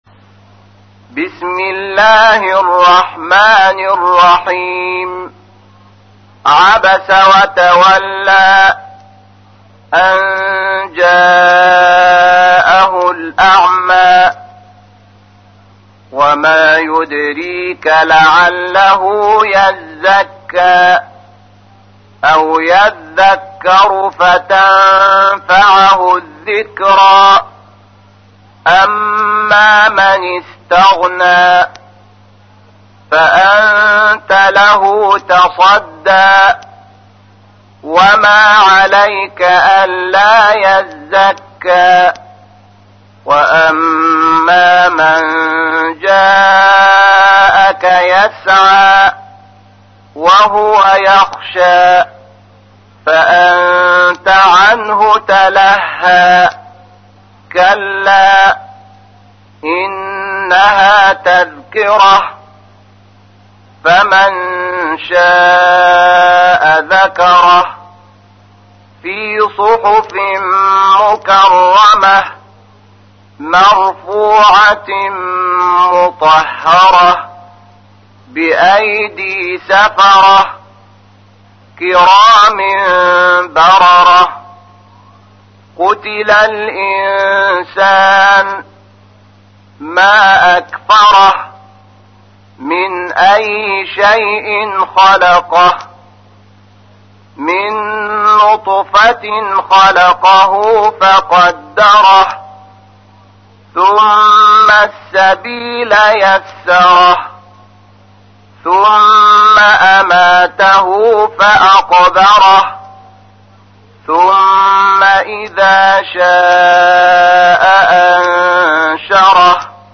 تحميل : 80. سورة عبس / القارئ شحات محمد انور / القرآن الكريم / موقع يا حسين